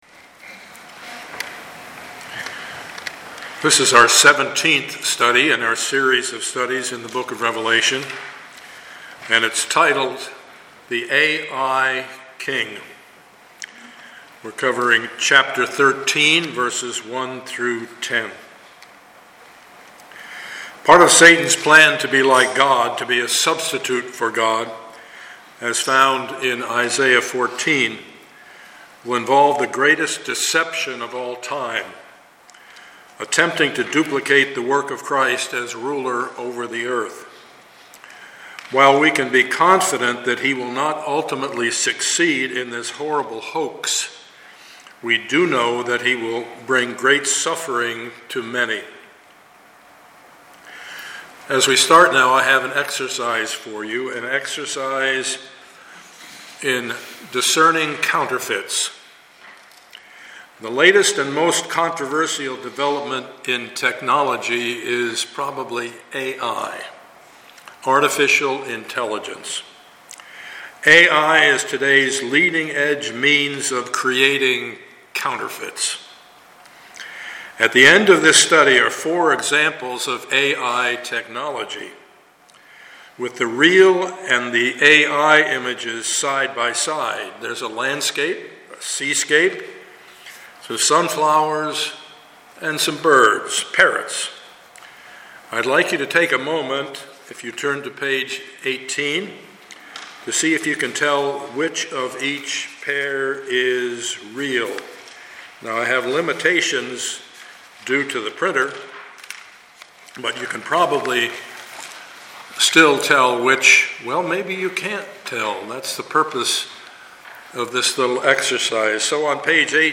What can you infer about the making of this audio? Passage: Revelation 13:1-10 Service Type: Sunday morning